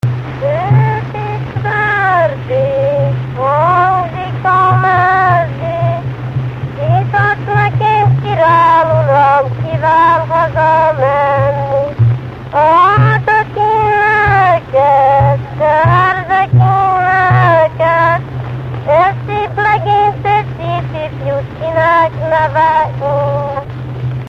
Dunántúl - Vas vm. - Farkasfa
Műfaj: Párosító
Stílus: 7. Régies kisambitusú dallamok
Szótagszám: 5.5.8.6
Kadencia: 1 (b3) 1 1